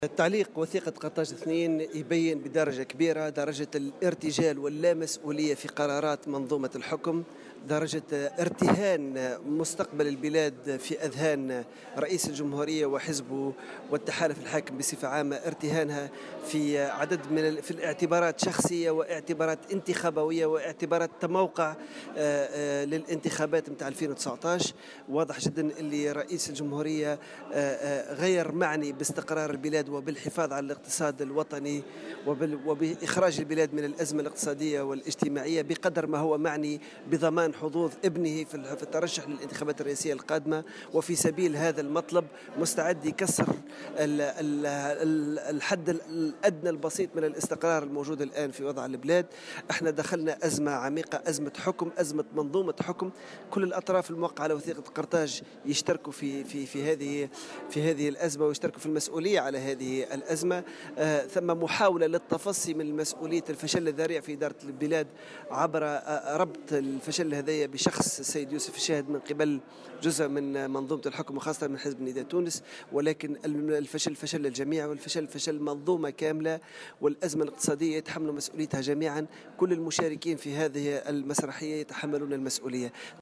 وأضاف في تصريح اليوم لمراسل "الجوهرة أف أم" أن الأوضاع الاقتصادية والاجتماعية المتردية ما زالت على حالها، مشيرا إلى أن حكومة الشاهد هي حكومة محاصصات وبلا برامج وحلول وأنه لن يتغيّر شيئا بمجرّد تغيير الأسماء.